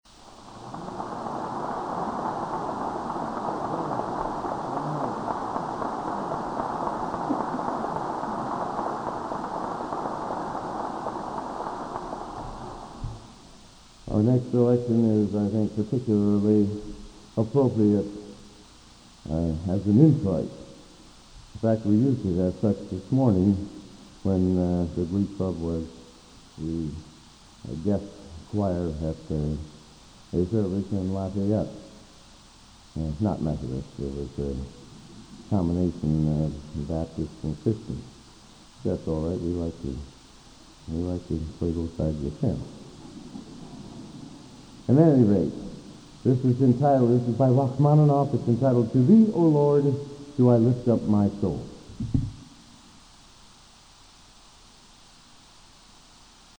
Collection: Broadway Methodist, 1982